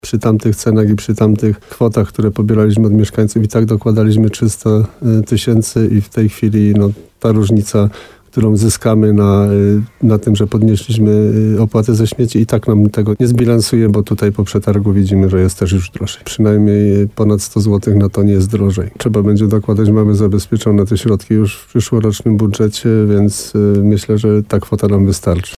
– Mimo podwyżki opłat samorząd i tak będzie musiał dopłacać do śmieci z budżetu – mówił w programie Słowo za Słowo na antenie RDN Nowy Sącz wójt gminy Rytro, Jan Kotarba.